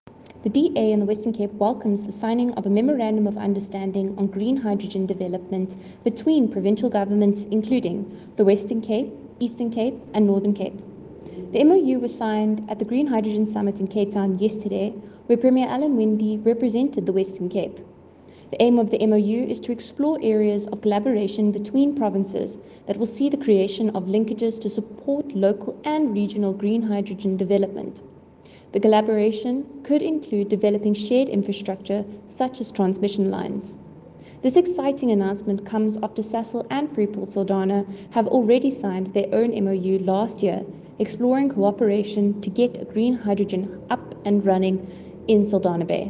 English soundbite from MPP Cayla Murray attached.